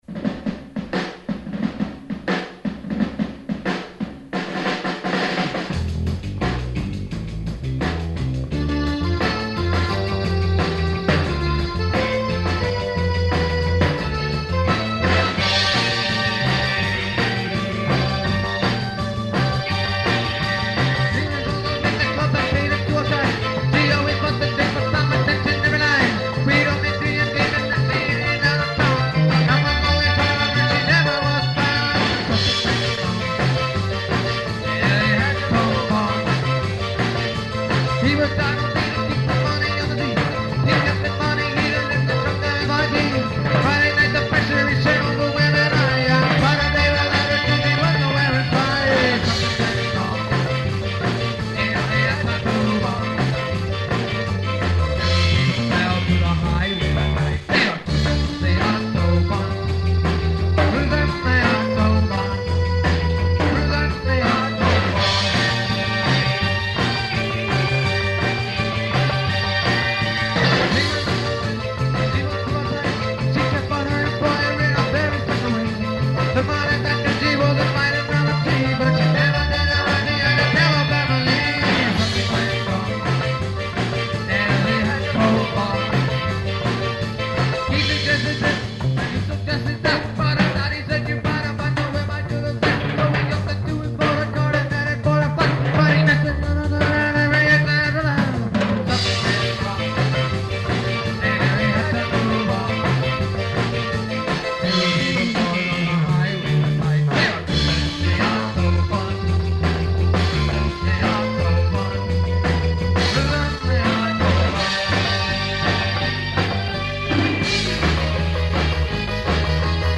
Keep in mind that these are from 20-year old cassette tapes, so sound quailty is definitely an issue. We apologize in advance for all the mistakes and questionable singing, but this was a practice session after all.
The band played some covers and mostly original material in the style of ska and reggae.